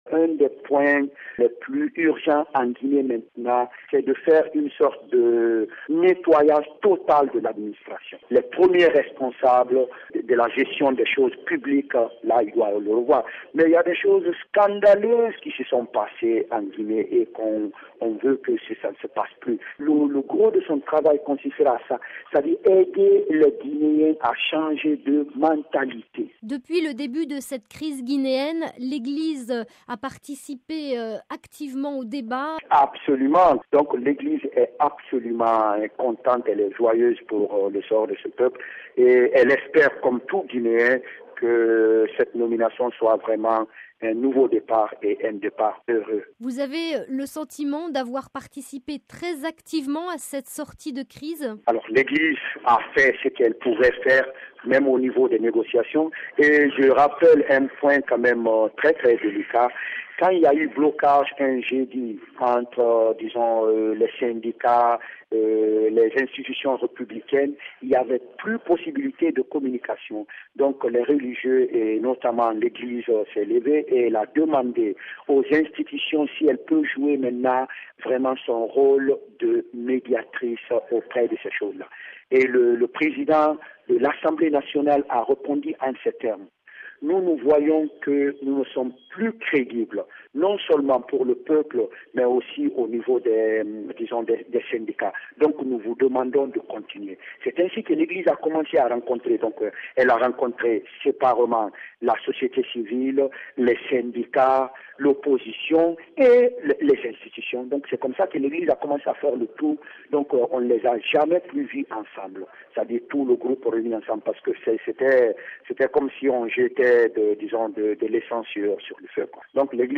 Propos recueillis